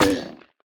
Minecraft Version Minecraft Version snapshot Latest Release | Latest Snapshot snapshot / assets / minecraft / sounds / mob / turtle / hurt2.ogg Compare With Compare With Latest Release | Latest Snapshot
hurt2.ogg